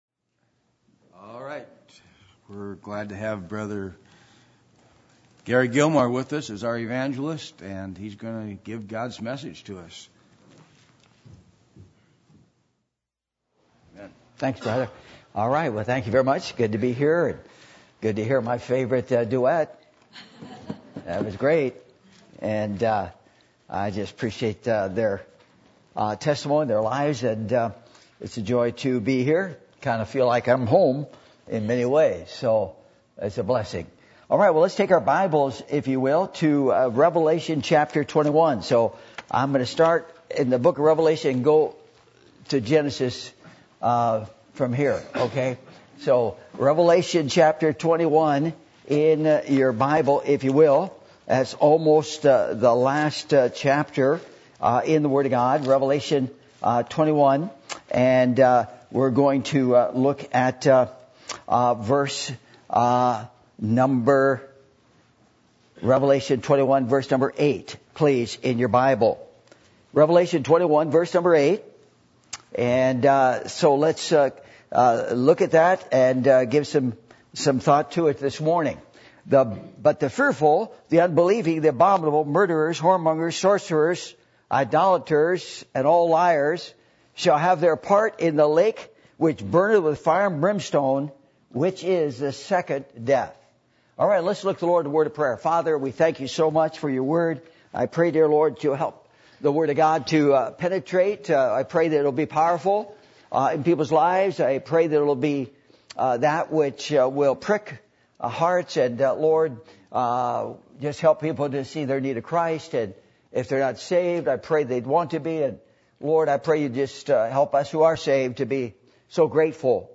Passage: Revelation 21:8 Service Type: Sunday Morning